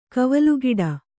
♪ kavalugiḍa